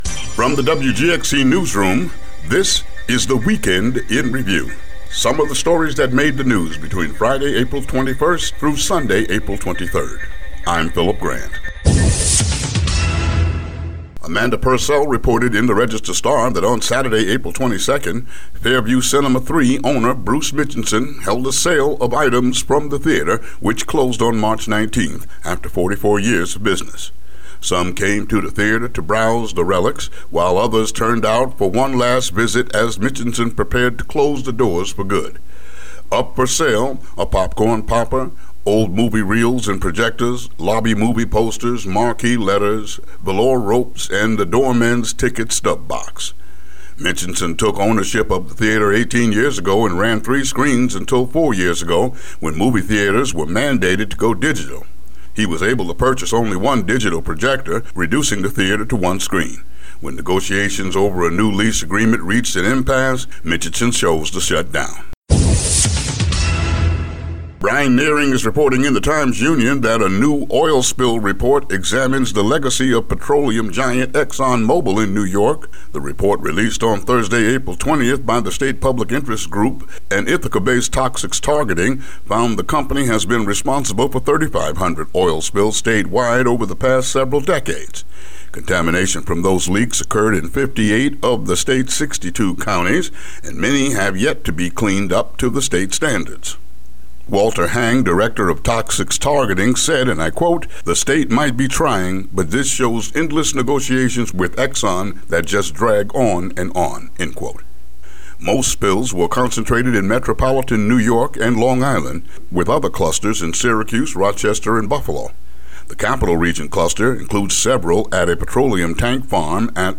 WGXC daily headlines for Apr. 24, 2017.